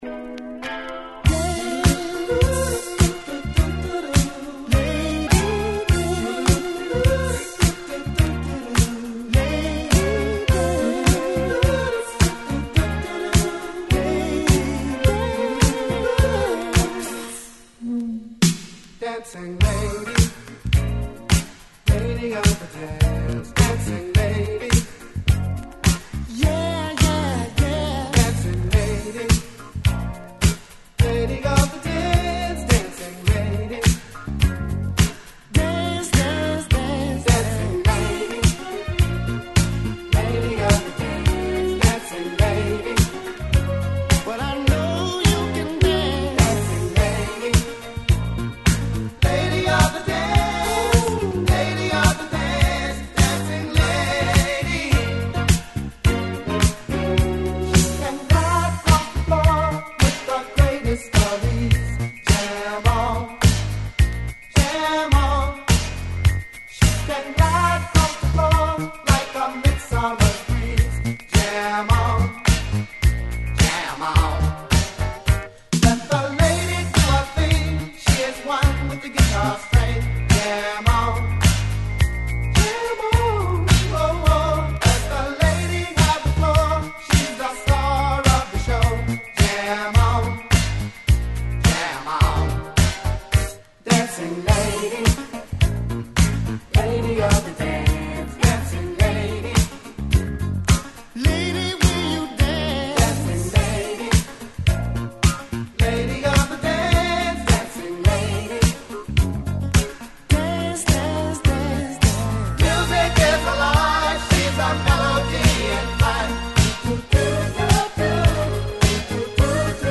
New Orleans産Obscure Disco~Boogie!!